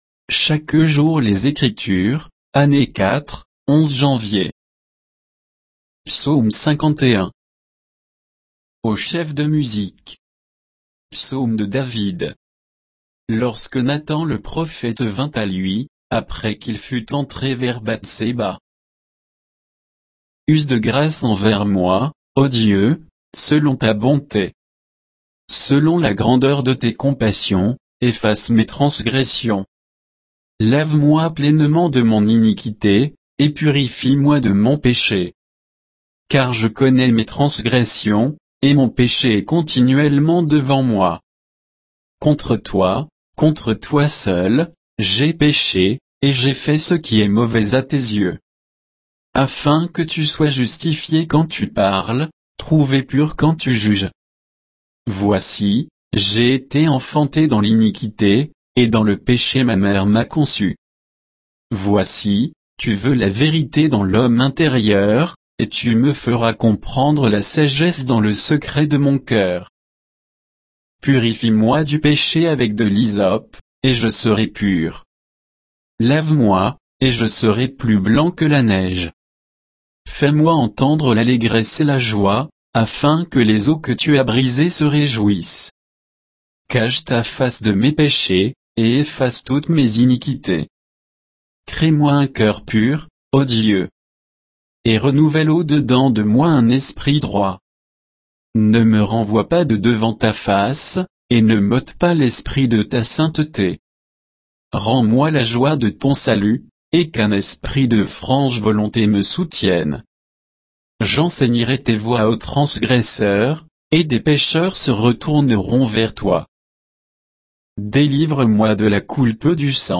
Méditation quoditienne de Chaque jour les Écritures sur Psaume 51